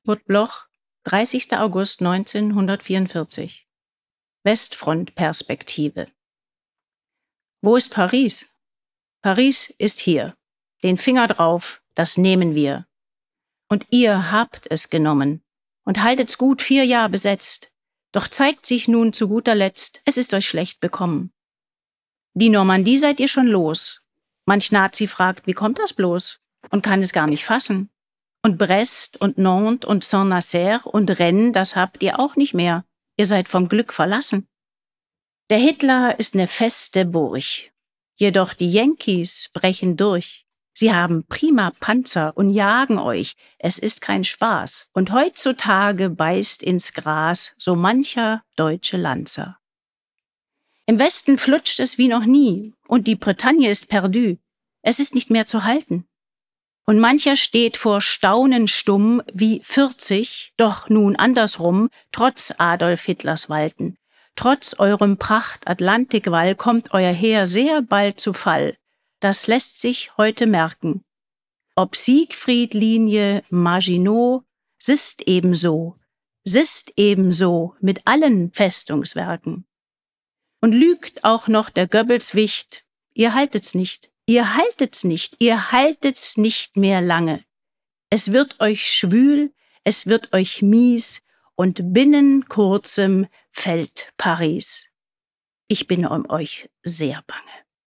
Karoline Eichhorn (* 1965) ist eine deutsche Schauspielerin und Hörspielsprecherin.